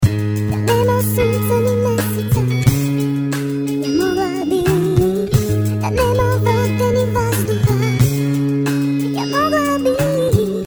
Stretch, helium